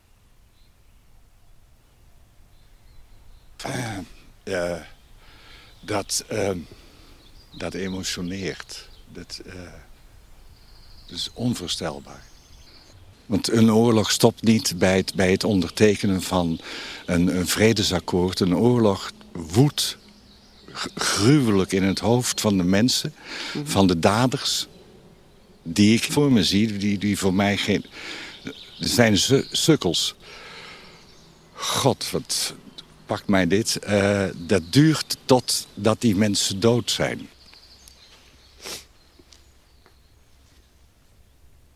theatrale audiotour